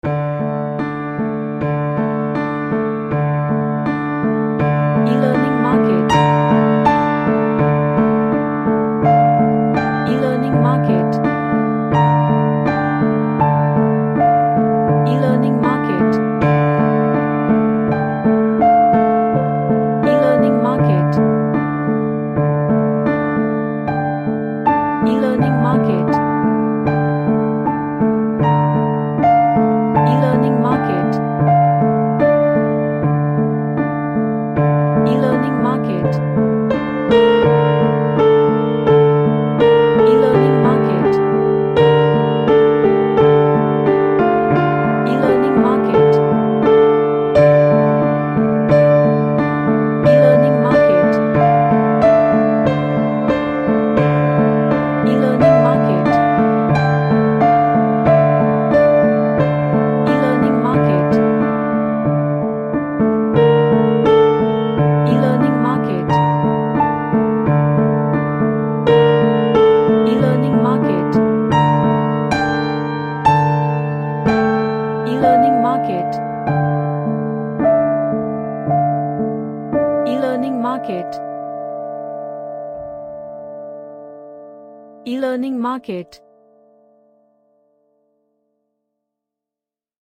A dark and sad piano track
Dark / SomberSad / Nostalgic